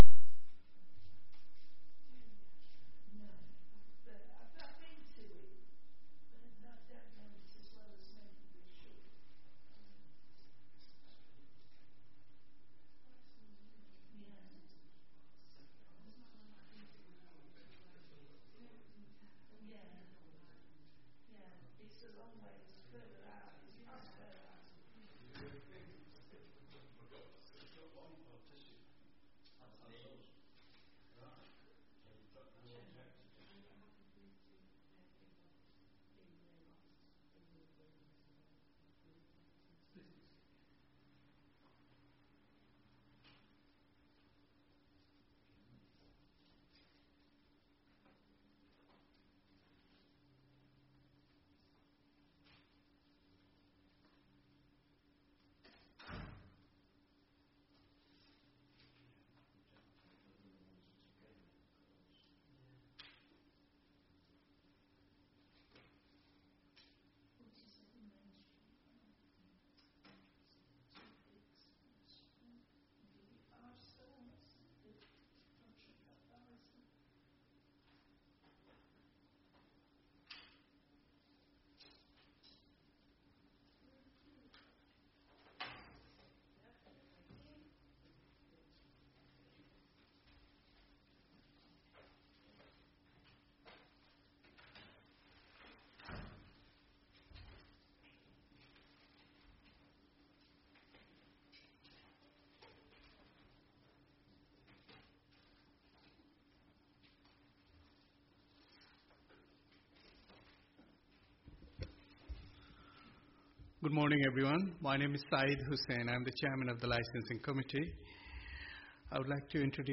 Committee Licensing Act Sub-Committee Meeting Date 04-11-24 Start Time 10.00am End Time 10.55am Meeting Venue Coltman VC Room, Town Hall, Burton upon Trent (no live stream available) Please be aware that not all Council meetings are live streamed.